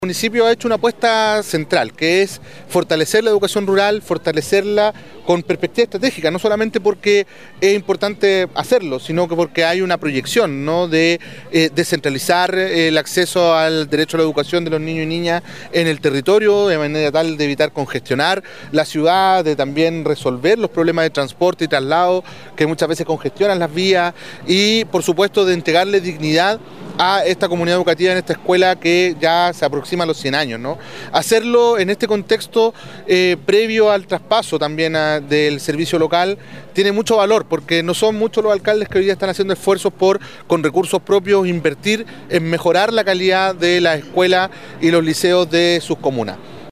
Ministro-Nicolas-Cataldo-destaca-inversion-municipal.mp3